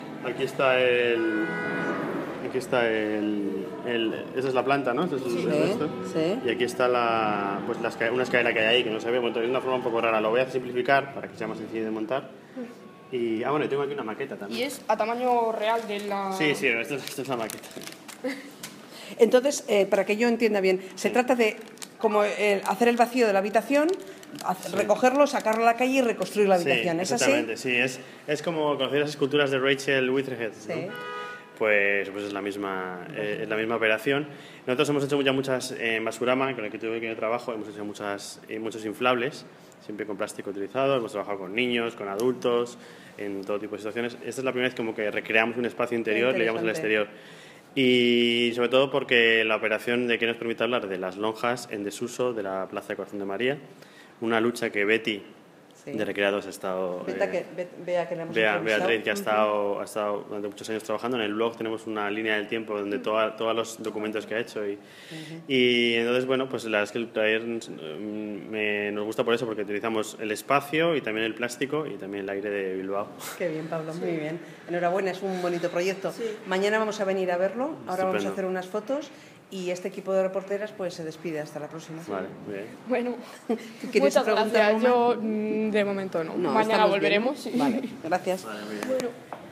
Entrevista 1: